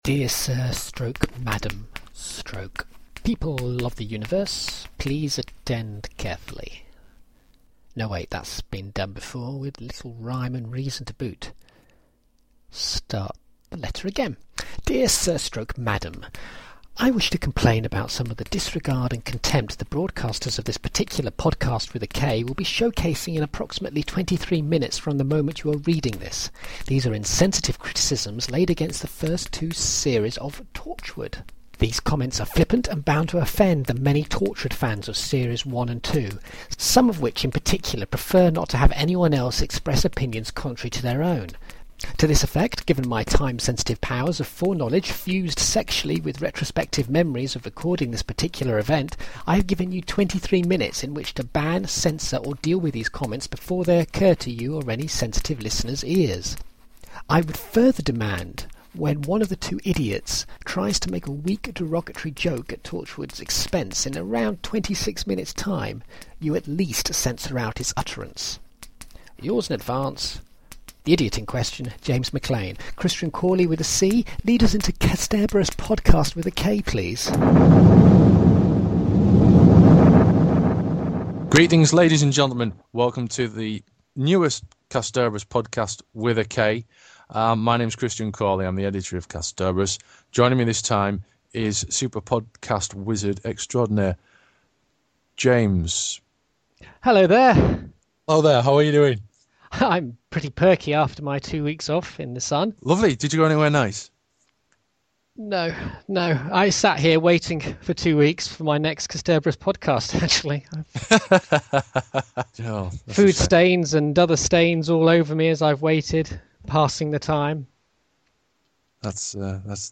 Archive podcast upload reviews the 2011 Doctor Who episode The Doctor's Wife, by Neil Gaiman and starring Matt Smith.